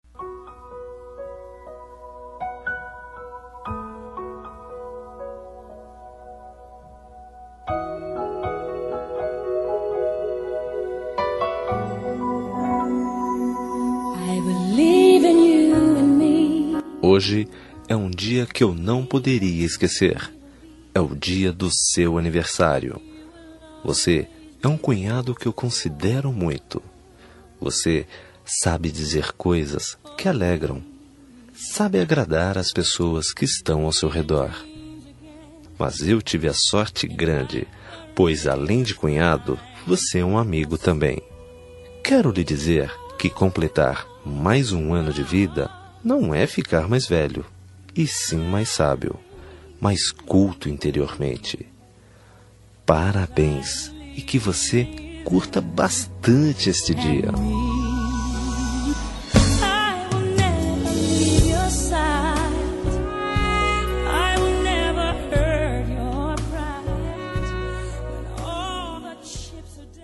Aniversário de Cunhado – Voz Masculina – Cód: 5229